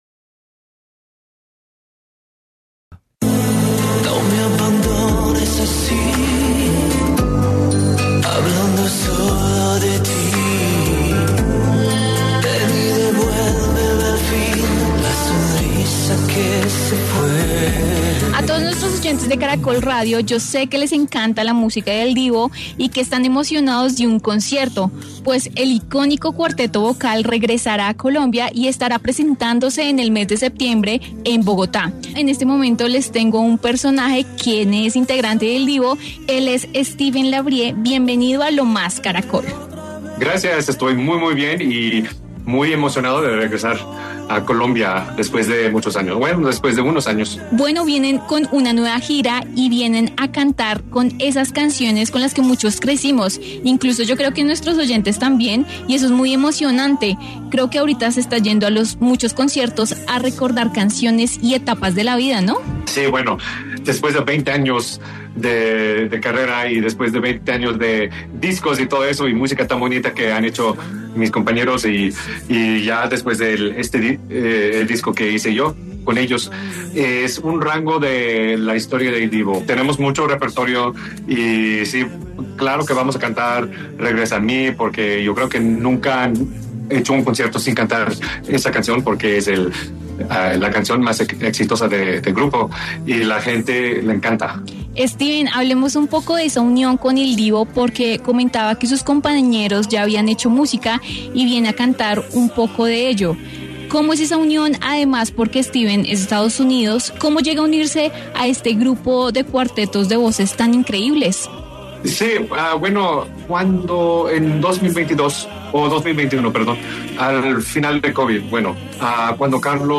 En los micrófonos de Caracol Radio, habló Steven LaBrie el nuevo integrante de Il Divo tras el fallecimiento en el 2021 del cofundador Carlos Marín y contó detalles del concierto en la capital.